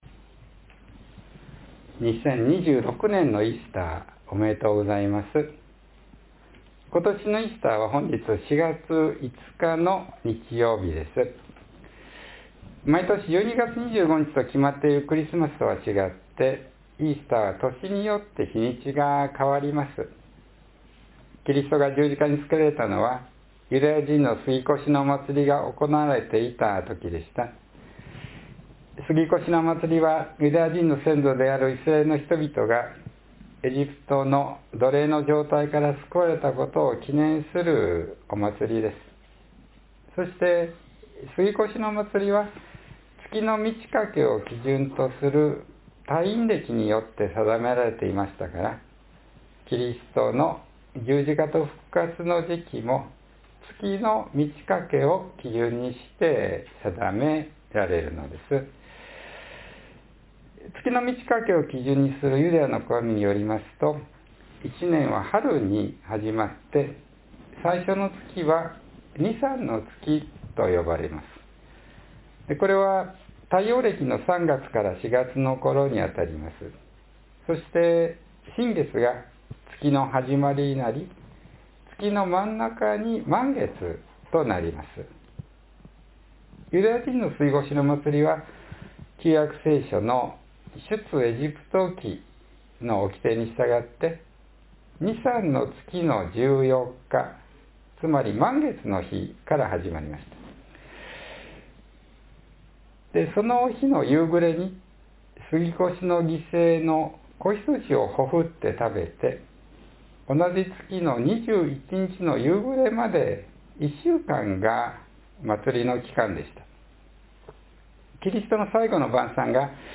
トマスはキリストの愛に出会うことによって疑う人から信じる人へと変えられたのでした。 （4月5日の説教より）